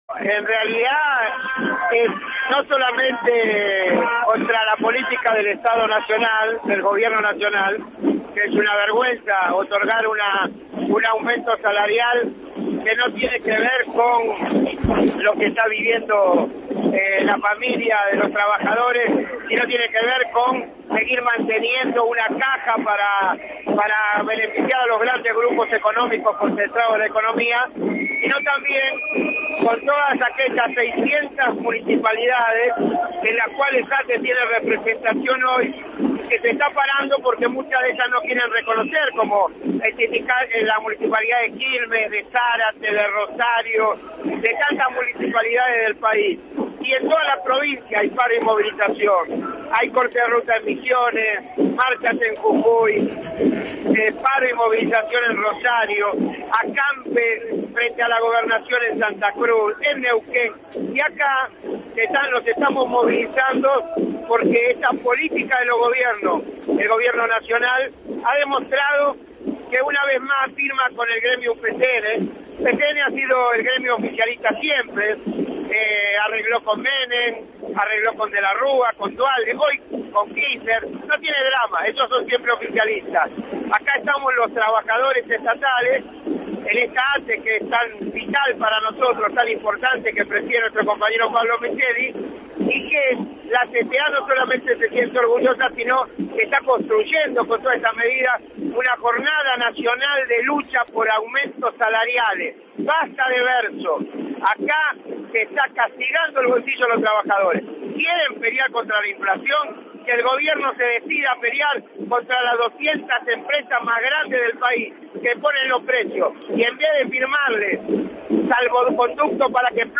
Entrevista a Víctor De Gennaro
Victor_De_Gennaro_en_Continental.mp3